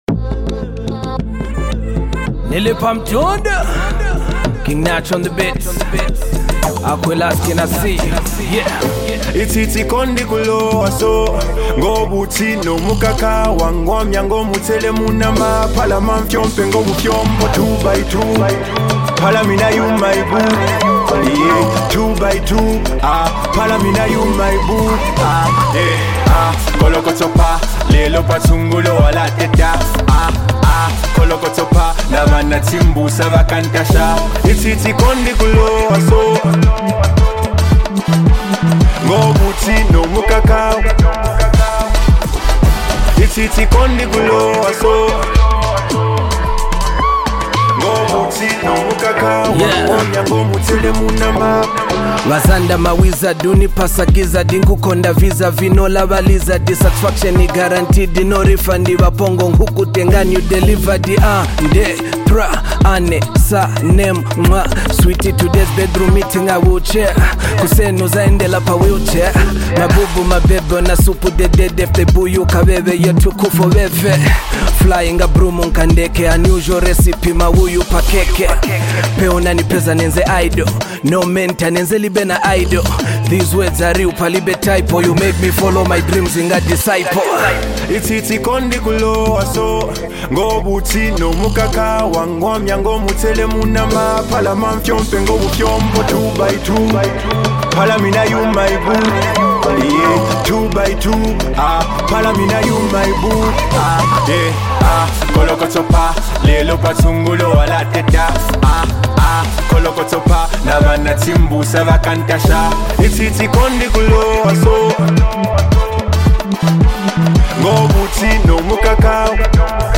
smooth vocals bring warmth to the track
is a beautiful blend of emotion and melody